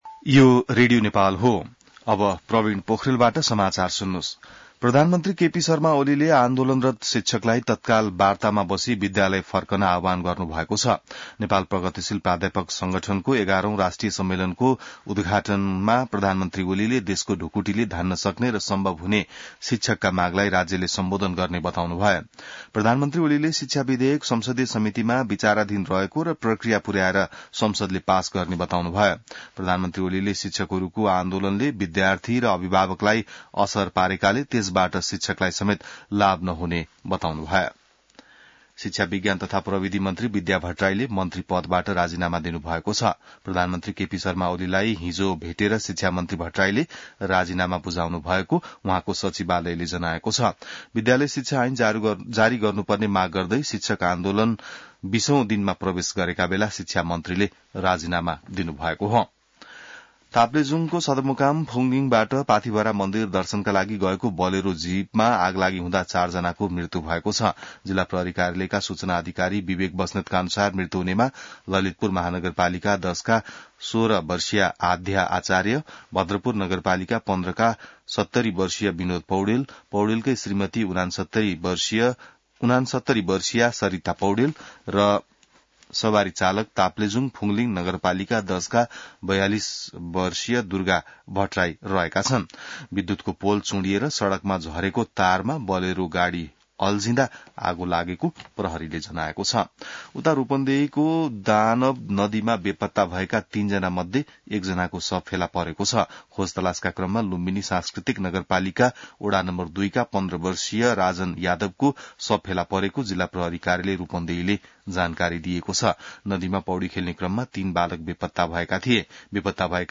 बिहान ६ बजेको नेपाली समाचार : ९ वैशाख , २०८२